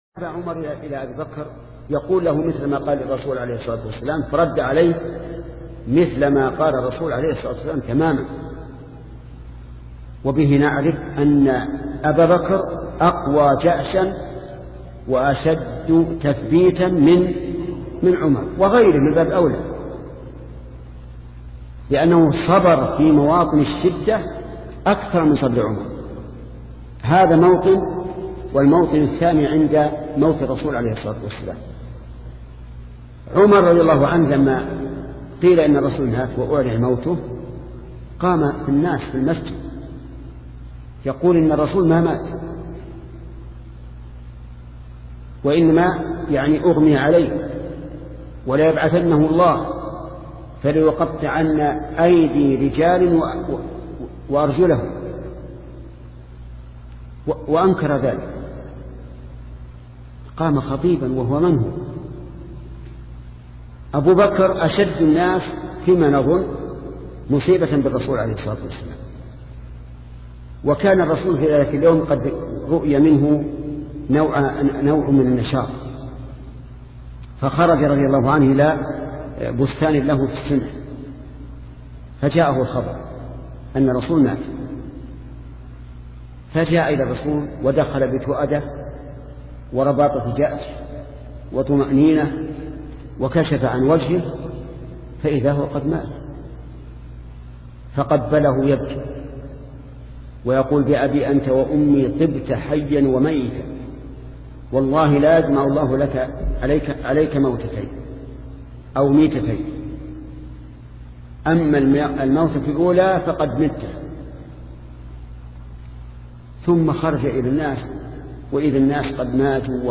شبكة المعرفة الإسلامية | الدروس | عقيدة أهل السنة والجماعة (11) |محمد بن صالح العثيمين